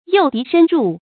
诱敌深入 yòu dí shēn rù 成语解释 诱：引诱。引诱敌人深深地进入我方利于围歼的地区。
成语繁体 誘敵深入 成语简拼 ydsr 成语注音 ㄧㄡˋ ㄉㄧˊ ㄕㄣ ㄖㄨˋ 常用程度 常用成语 感情色彩 中性成语 成语用法 连动式；作谓语、补语；用于军事方面 成语结构 连动式成语 产生年代 当代成语 成语正音 诱，不能读作“ròu”。